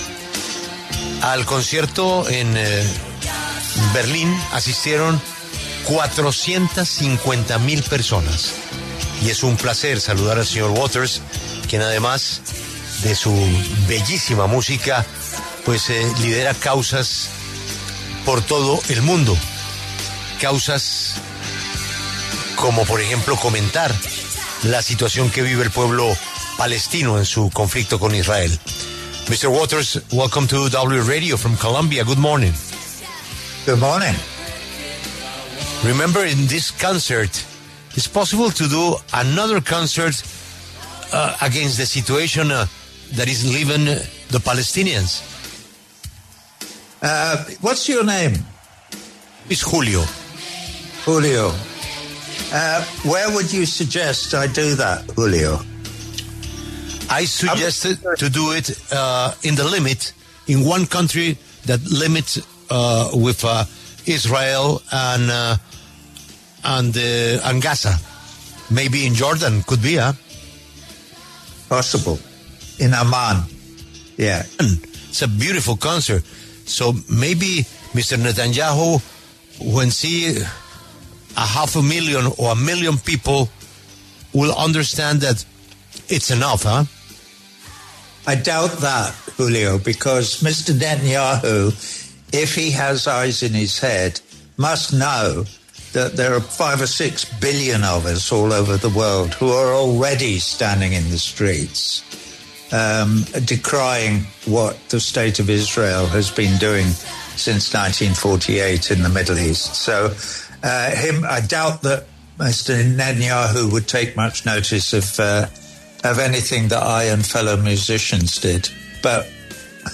Roger Waters, cantautor, estuvo en entrevista con La W y se refirió al genocidio en Gaza, la situación en Venezuela, las deportaciones de Estados Unidos y una posible guerra nuclear.